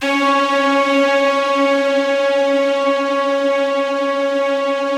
BIGORK.C#3-R.wav